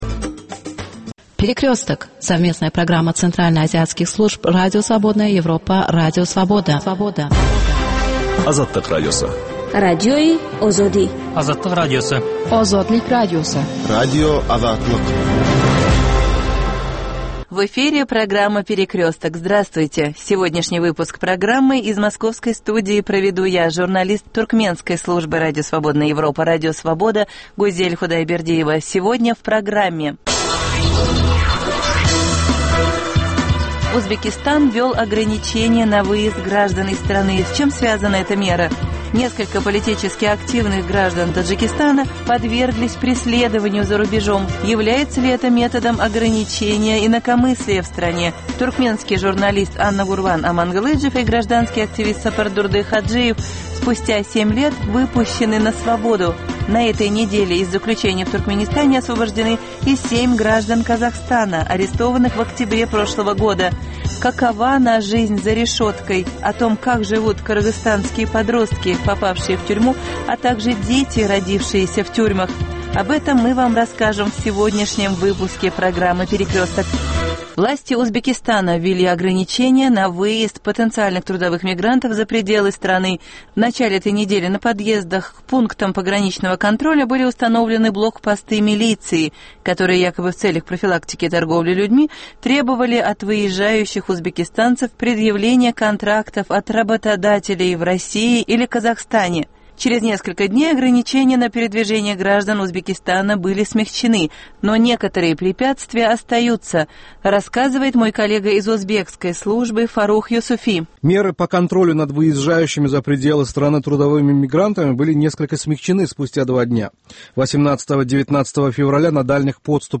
Новости стран Центральной Азии.